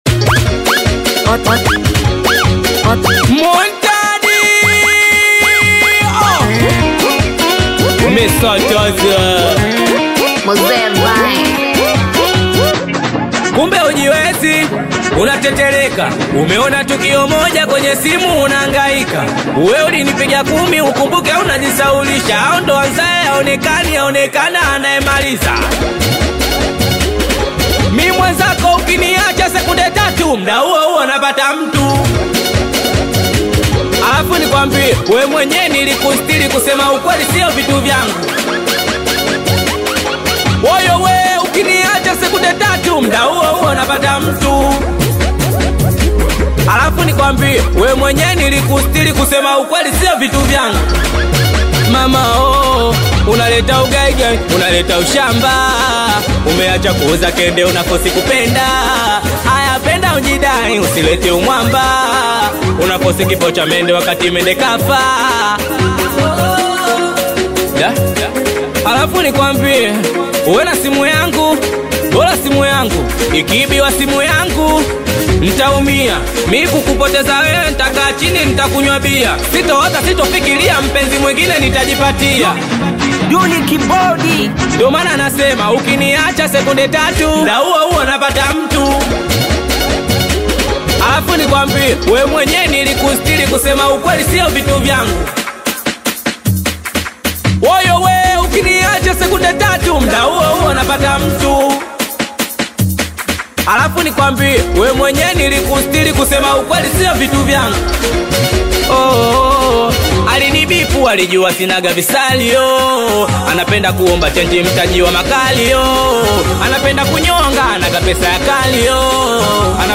Singeli music track
Tanzanian Bongo Flava artist, singer, and songwriter
Singeli song